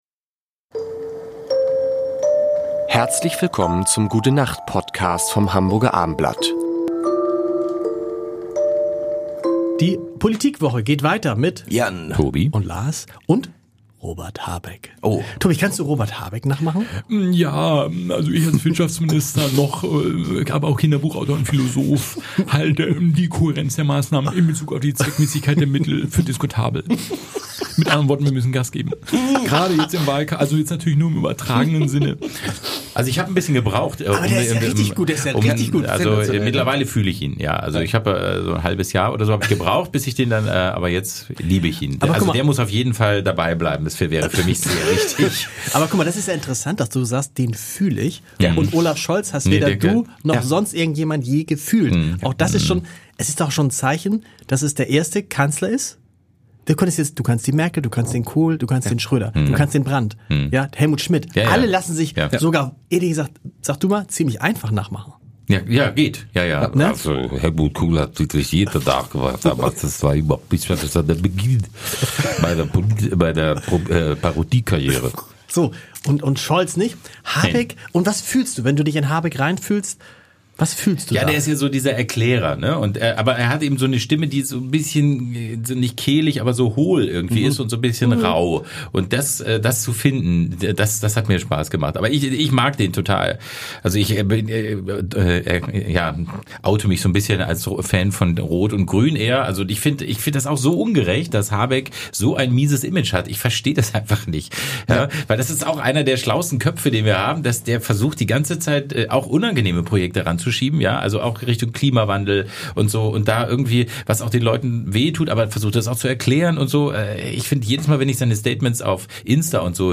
Sie erwarten unterhaltsame, nachdenkliche und natürlich sehr musikalische fünf Minuten.